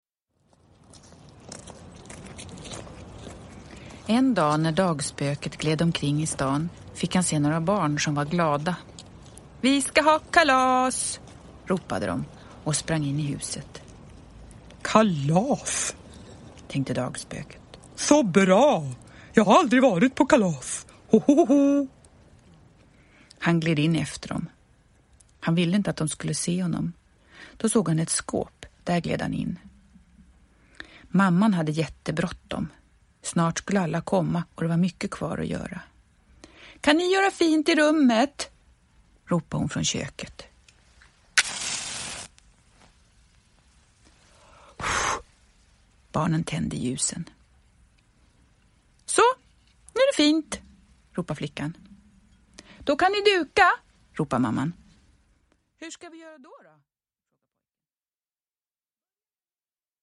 Dagspöket på kalas – Ljudbok – Laddas ner
Produkttyp: Digitala böcker